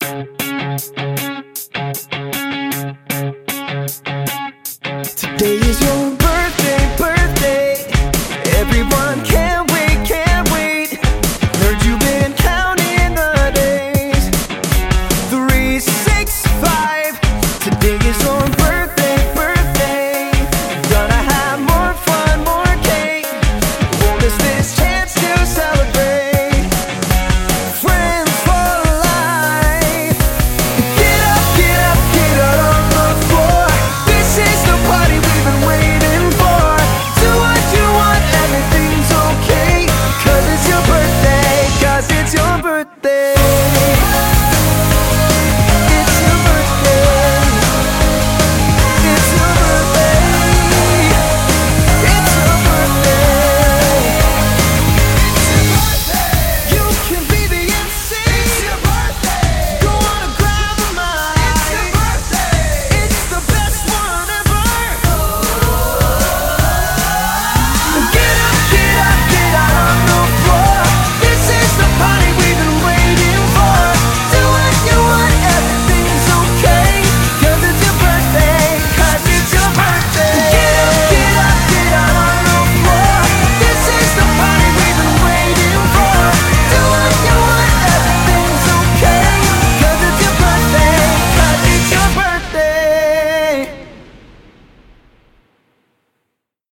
BPM155
Audio QualityPerfect (High Quality)
Comments[BIRTHDAY SONG]